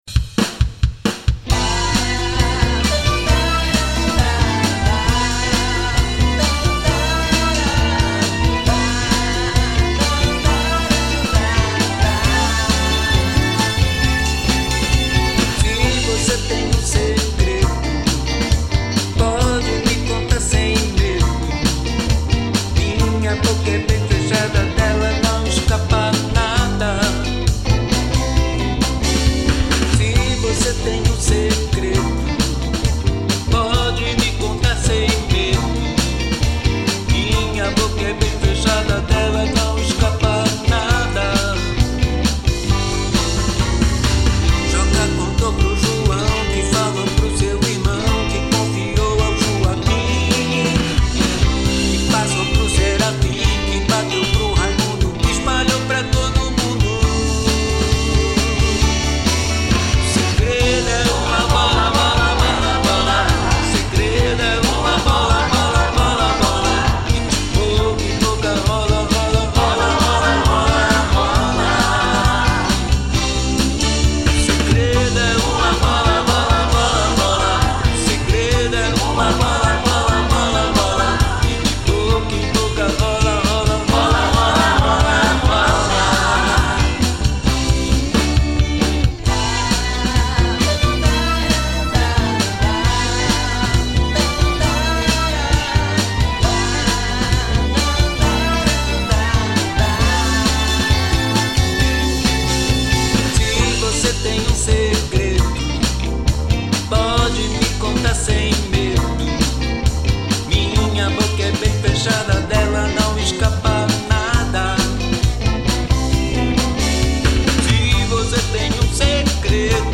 EstiloBlues